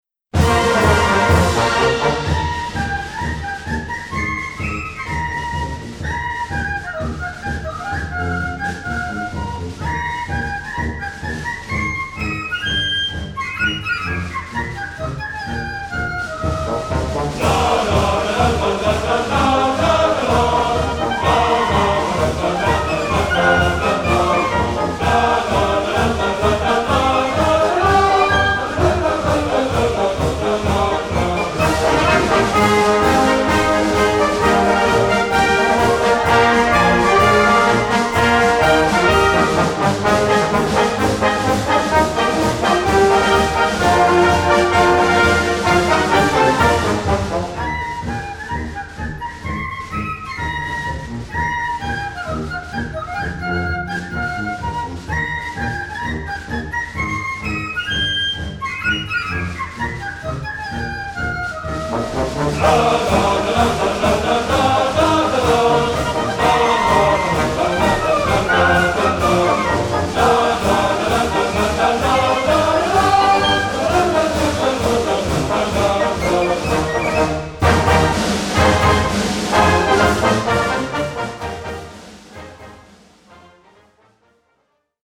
Gattung: Filmmusik
Besetzung: Blasorchester
Endlich ein Marsch mit einer Prise Humor.